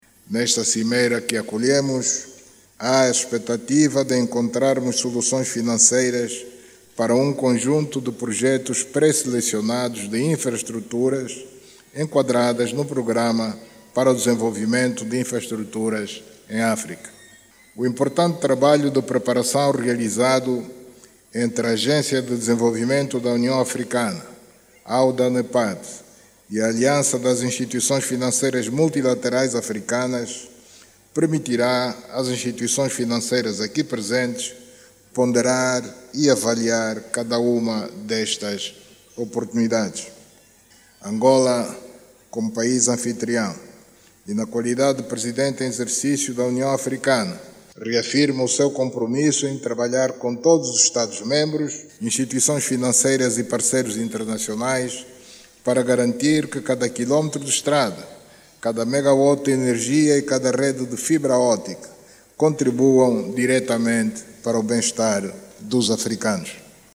A sessão de abertura da Cimeira de Luanda, realizada nesta terça-feira, marcou o início de um importante debate sobre o financiamento de infraestruturas em África.
Presidente da República João Lourenço, ontem quando procedia a abertura da terceira Cimeira sobre desenvolvimento de infraestruturas em África.